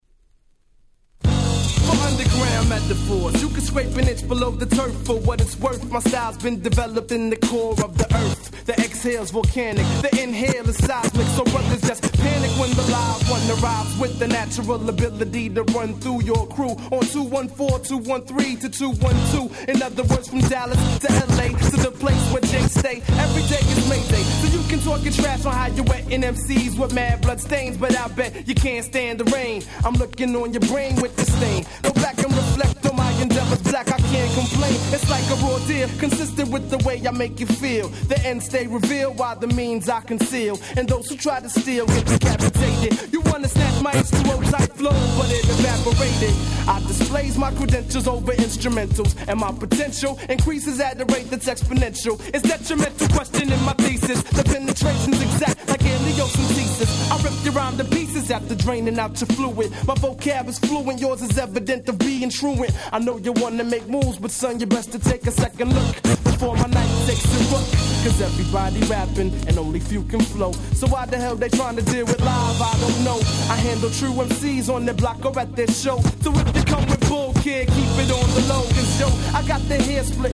95' Smash Hit Underground Hip Hop !!